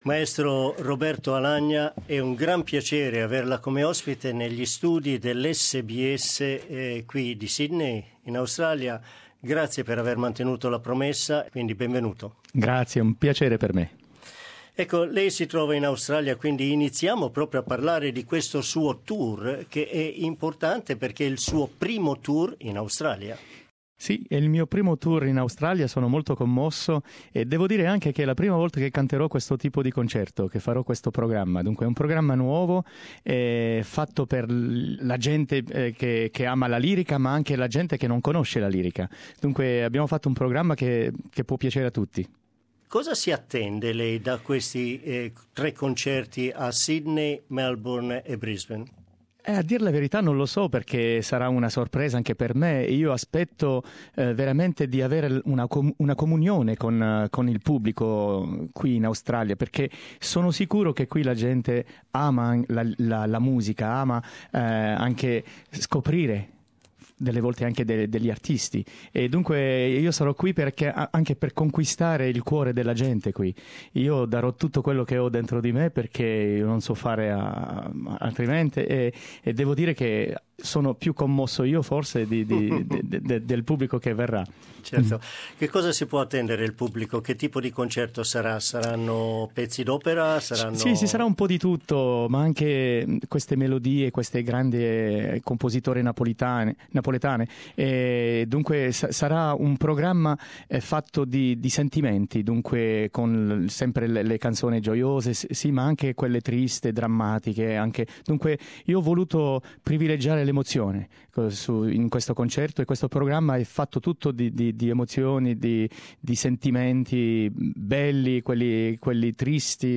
French-Italian tenor Roberto Alagna is finally in Australia to perform in Sydney, Melbourne and Brisbane and to meet some distant relatives who migrated to Australia many years ago for the first time. In this interview the opera superstar talks about his tour and his difficult experience as a migrant child in conservative France in the 1970s.
Roberto Alagna in our Sydney studios Source: SBS Italian